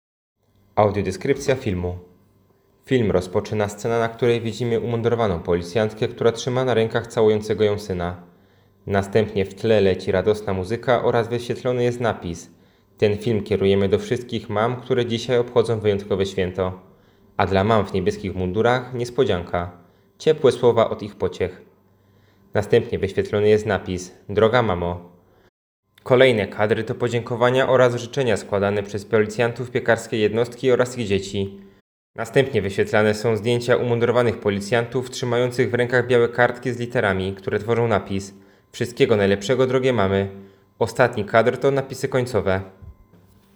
Opis nagrania: Audiodeskrypcja filmu